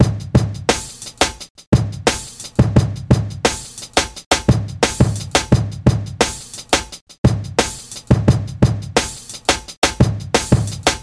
爵士跳鼓
描述：这些鼓是来自Fantom X6的。
标签： 87 bpm Rap Loops Drum Loops 1.86 MB wav Key : Unknown
声道立体声